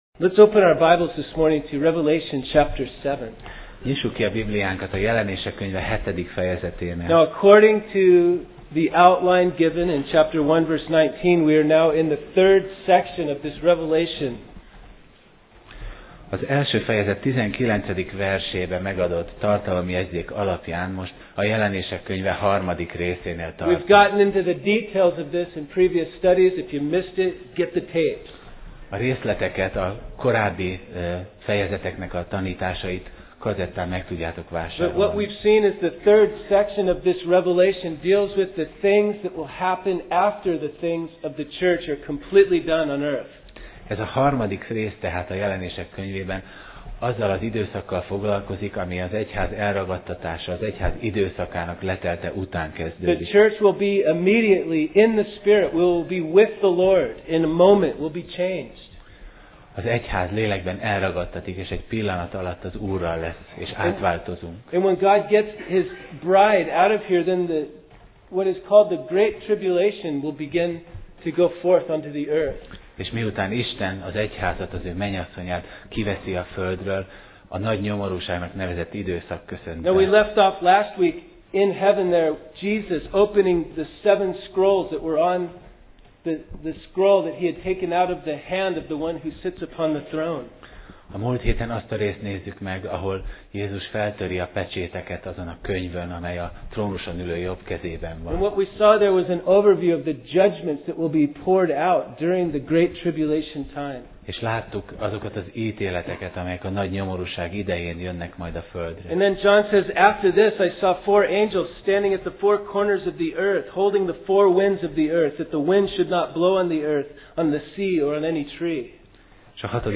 Passage: Jelenések (Revelation) 7:1-8 Alkalom: Vasárnap Reggel